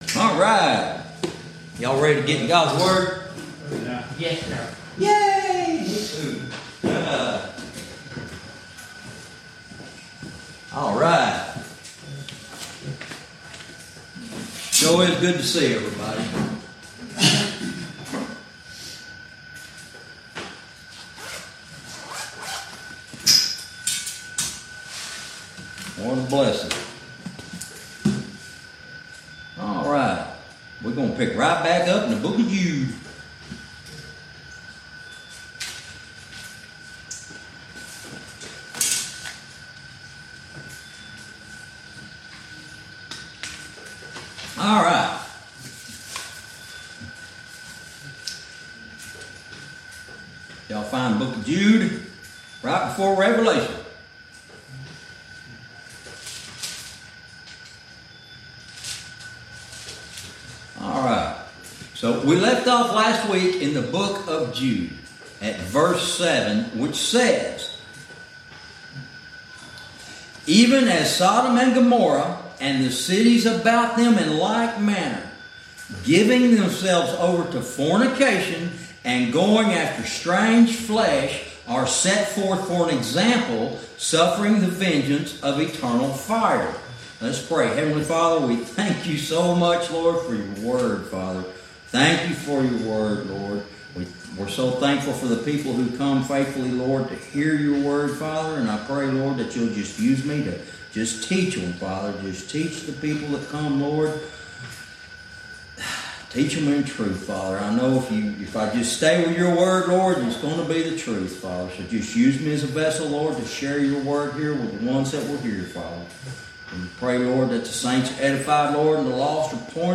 Verse by verse teaching - Lesson 20